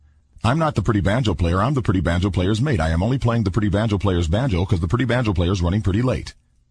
tongue_twister_02_02.mp3